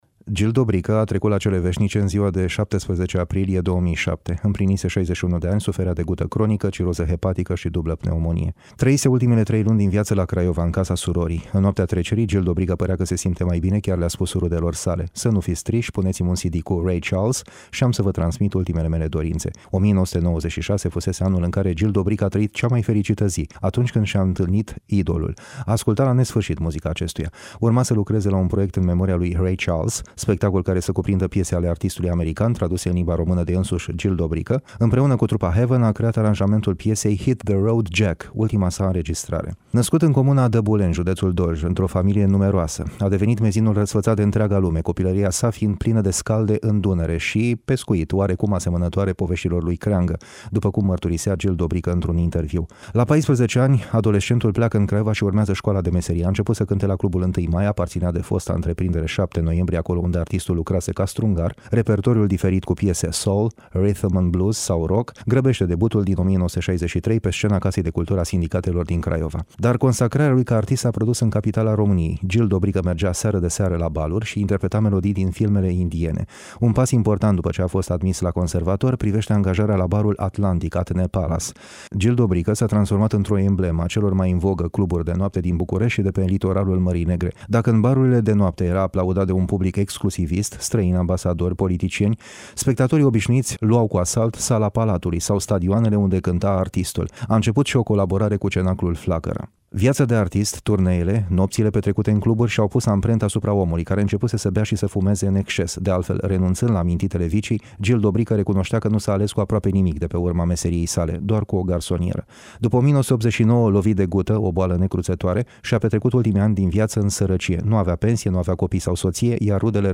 L-am avut oaspete , la Radio Iași…